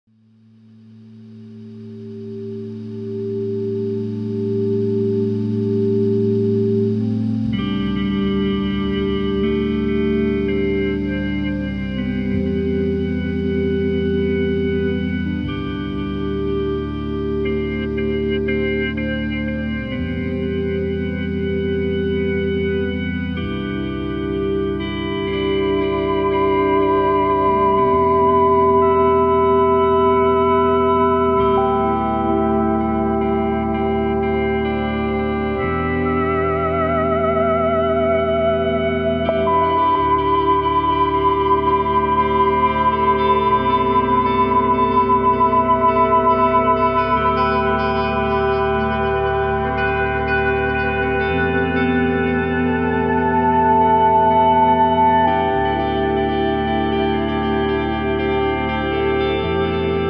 Relaxing Meditation & Peaceful Retreat
Atmospheric nature sounds & soft music